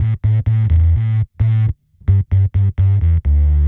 Index of /musicradar/dub-designer-samples/130bpm/Bass
DD_PBassFX_130A.wav